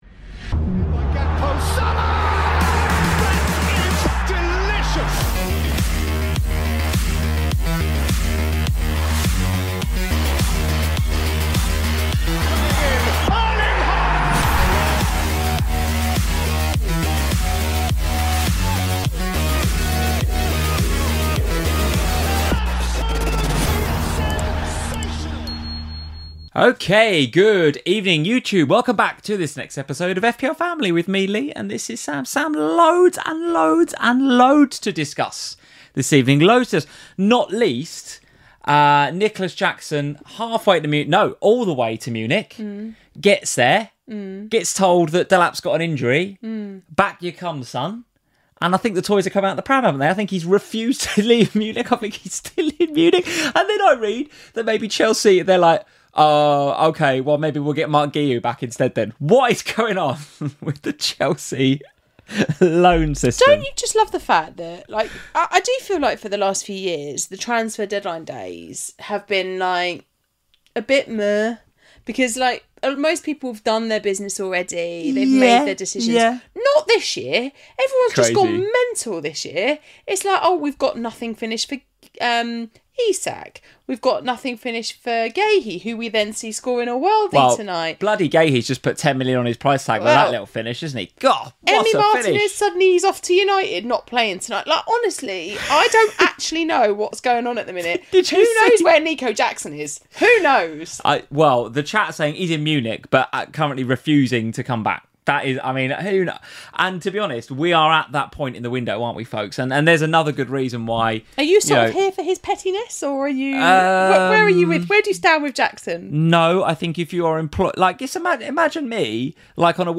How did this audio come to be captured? What a week in the Premier League, so much action to discuss, this could be a long stream - strap in folks! Loads of FPL discussion to be hard too, with Joao Pedro owners happy with the points, but not with Enzo taking the penalty.